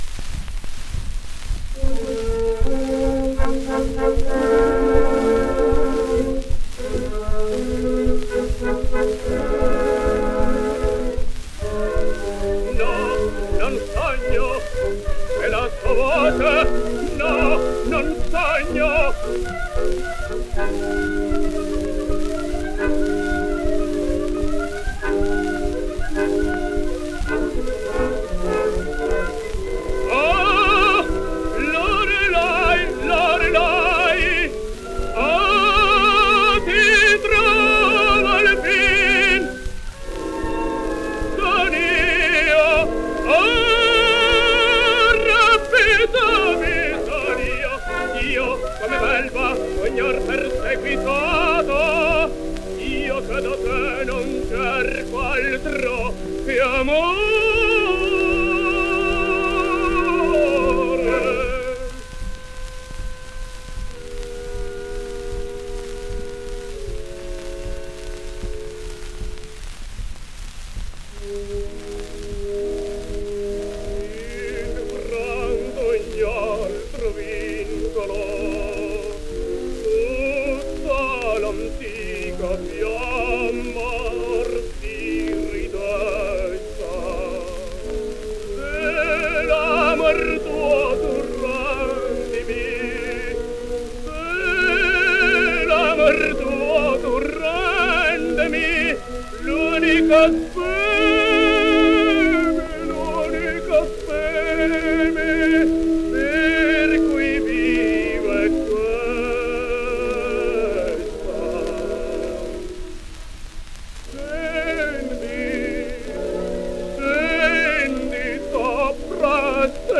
Franco Lo Giudice sings Loreley: